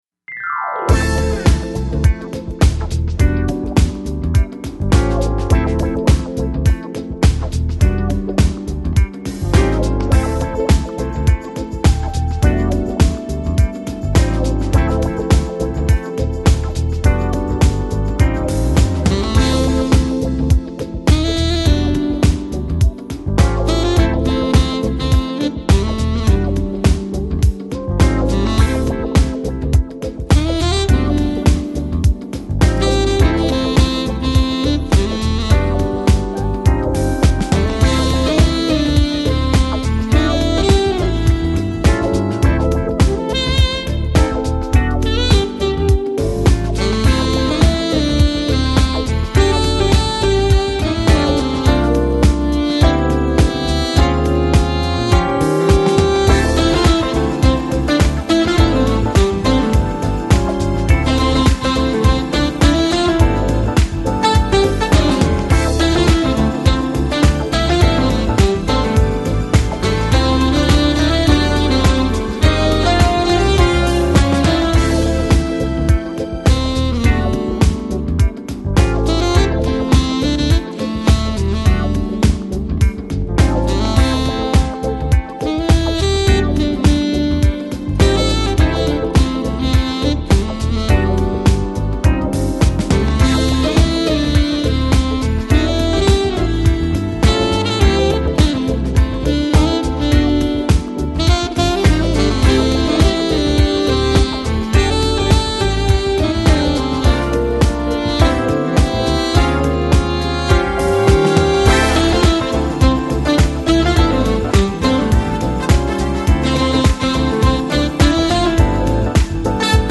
Жанр: Smooth Jazz